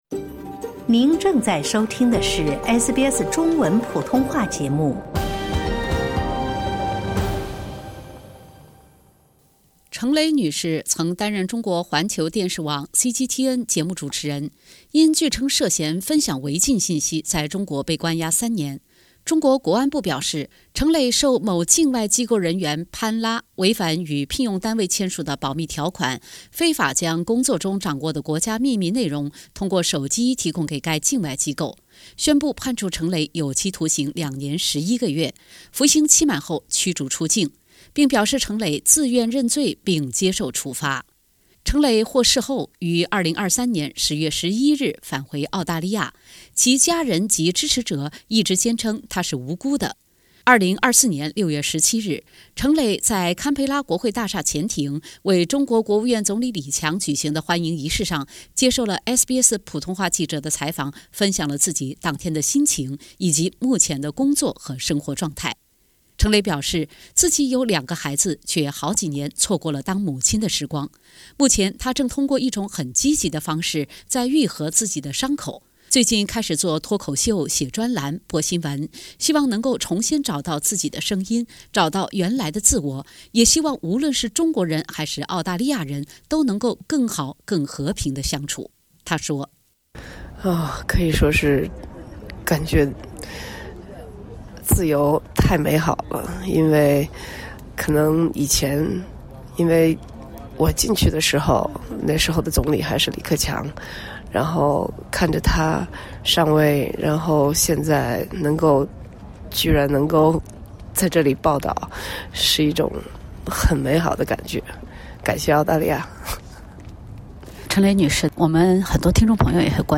成蕾女士6月17日早间在国会大厦前庭举行的中国国务院总理李强欢迎仪式上接受了SBS普通话记者的采访。